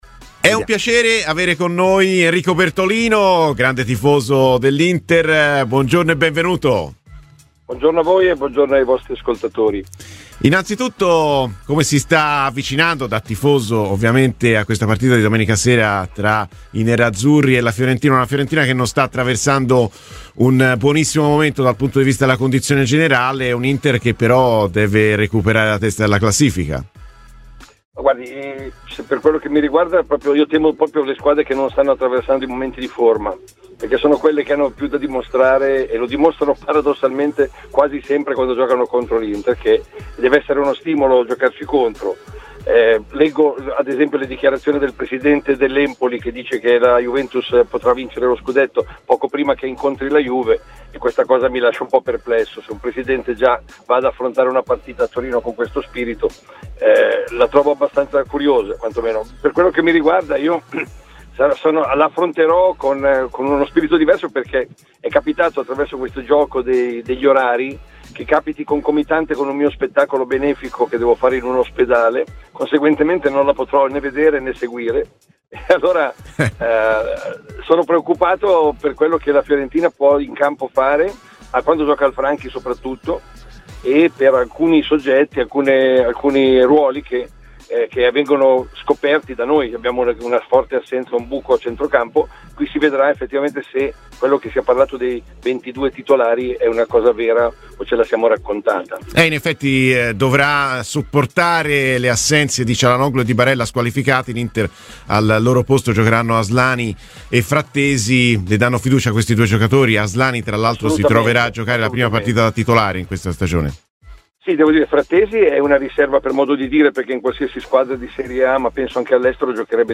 A tre giorni da Fiorentina-Inter è intervenuto in diretta su Radio FirenzeViola il comico, cabarettista e grande tifoso interista Enrico Bertolino.